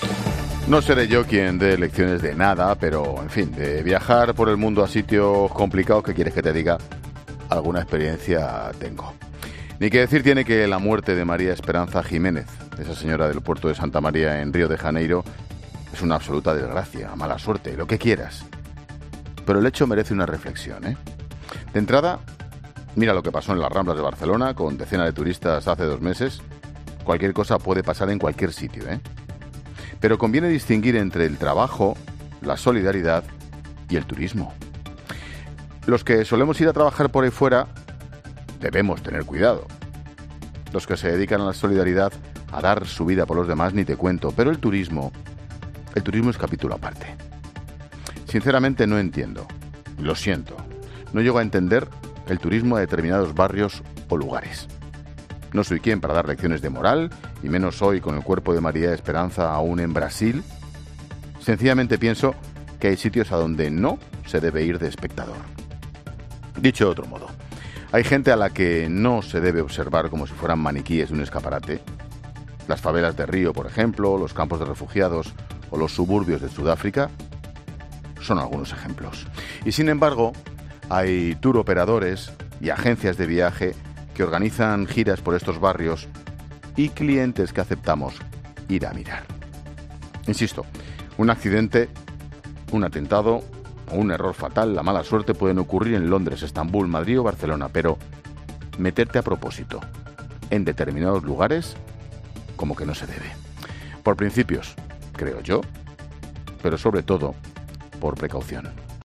Monólogo de Expósito
Ángel Expósito analiza en su monólogo de las 18 horas la muerte de una turista española en una favela de Rio de Janeiro.